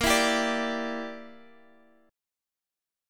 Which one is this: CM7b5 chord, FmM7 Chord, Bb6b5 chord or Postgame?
Bb6b5 chord